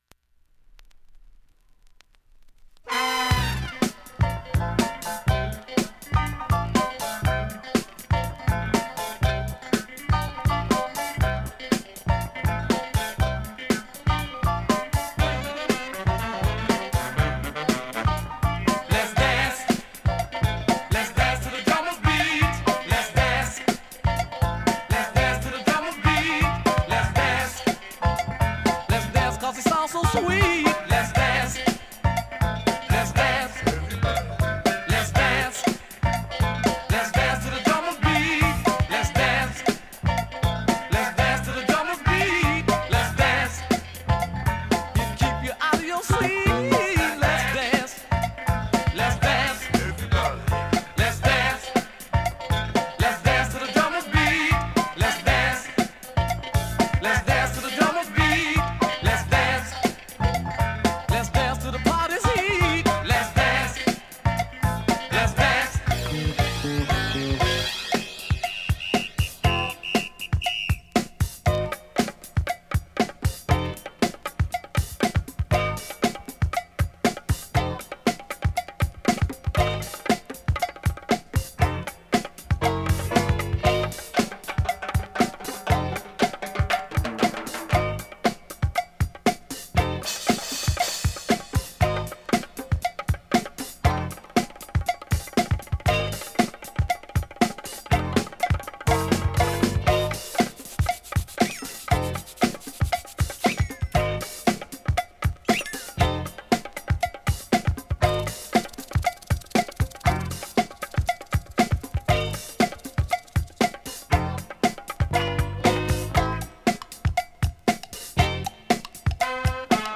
現物の試聴（両面すべて録音時間６分１１秒）できます。
ベーシック・オールドスクール・ブレイク